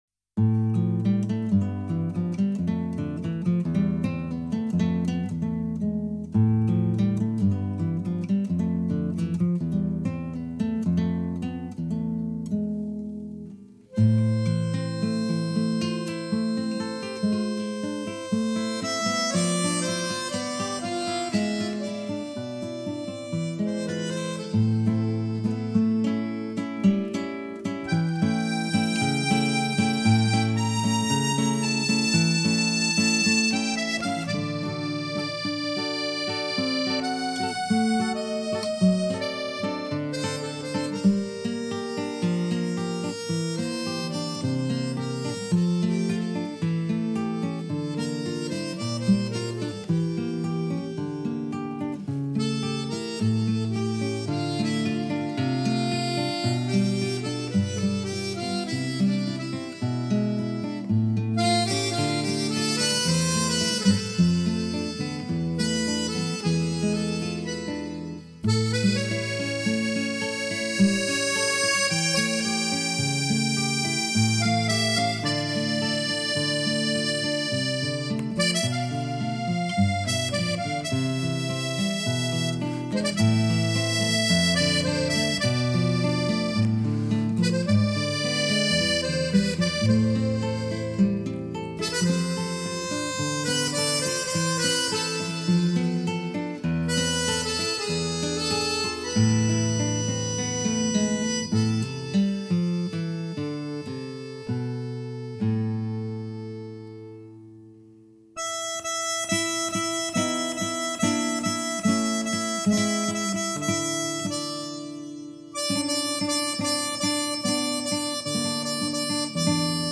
缠绵的慢板为主，流淌于手指间的心手合一力作，绝对动人心魄，感人肺腑